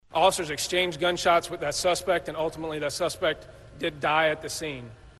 ディクテーション VOA News 23年４月11日午後８時（現地時間）のニュース 米国南東部ケンタッキー州最大の都市ルイビルで銃撃が起き、複数人が死傷したという話題です。